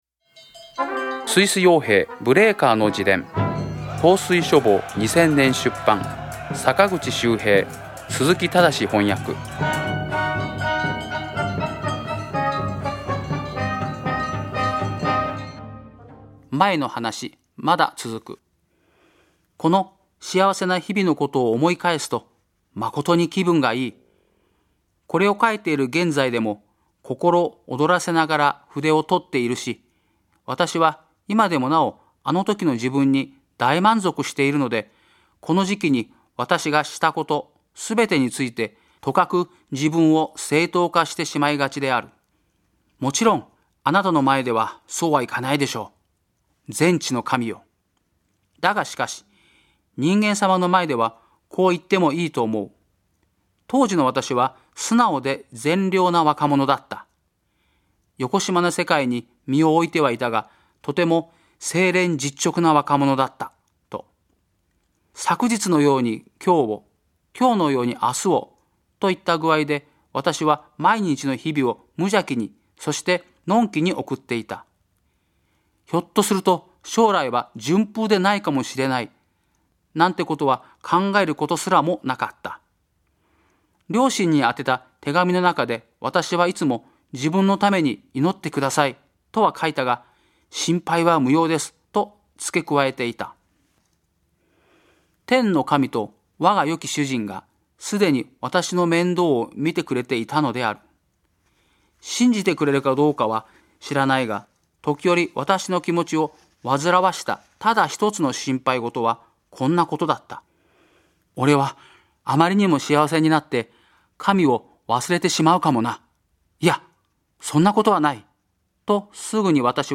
朗読『スイス傭兵ブレーカーの自伝』第44回